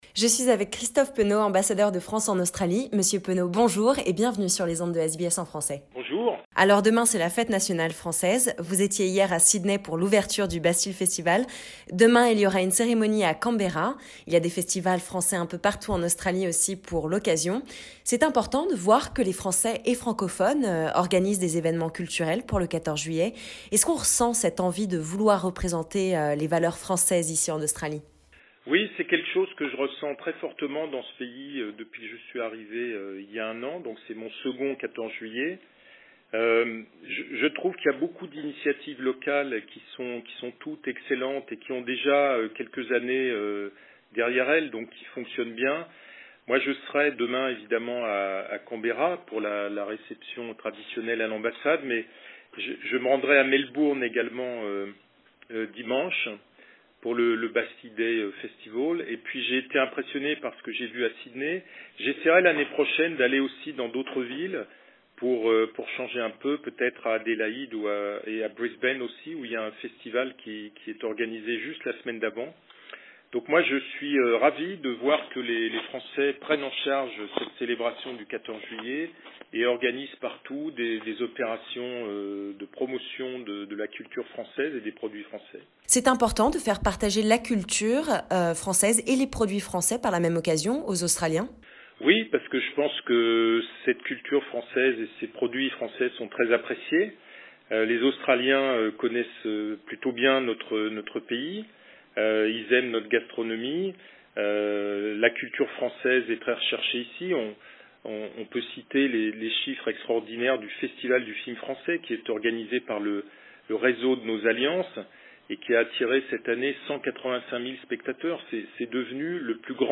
The Ambassador of France in Australia addresses his best wishes for the 14th of July and the World Cup!